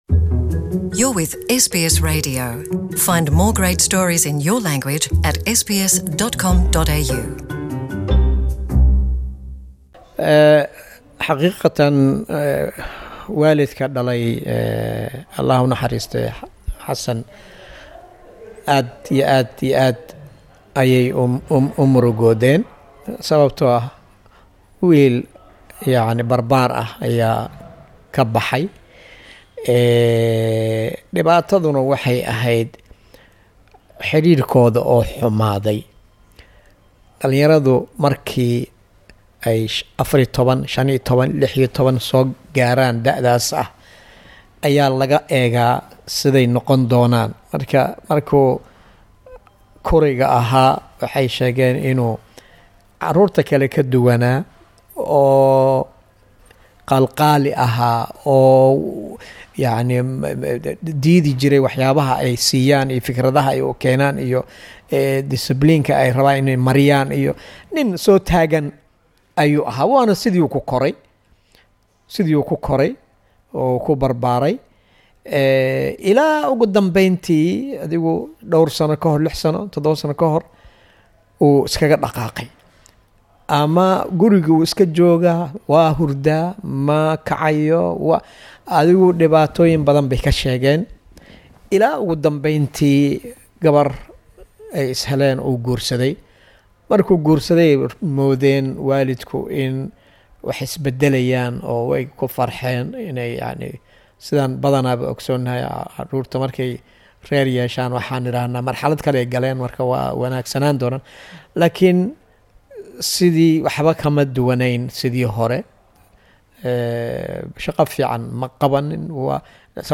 Interview
Waraysi